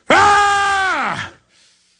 Play Alex Jones: Scream 2 - SoundBoardGuy
alex-jones-scream-2.mp3